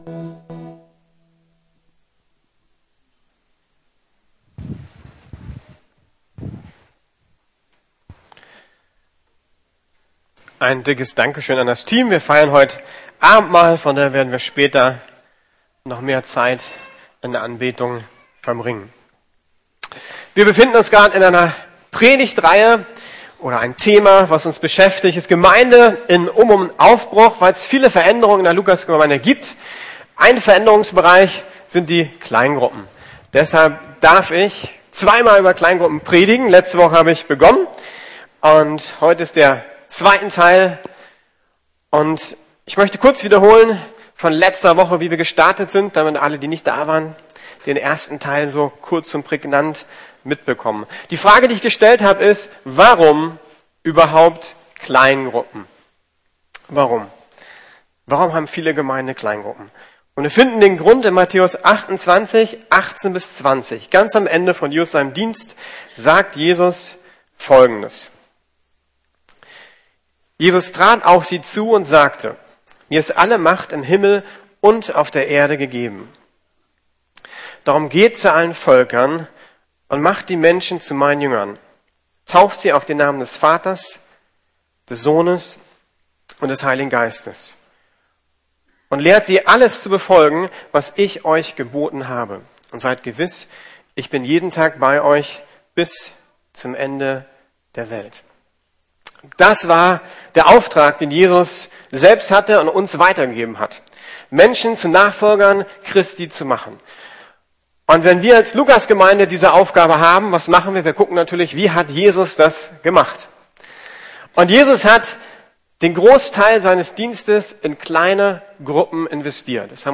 Gemeinsam geistlich wachsen - Teil 2 ~ Predigten der LUKAS GEMEINDE Podcast